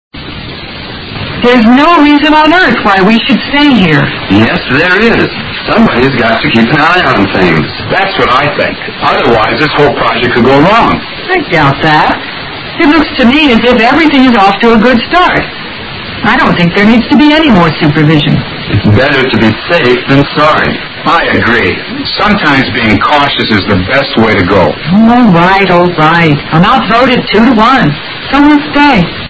英语对话听力mp3下载Listen 29:BETTER SAFE THAN SORRY
DIALOG 29